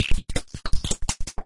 描述：用各种声音分层的fruty循环制作的鼓循环。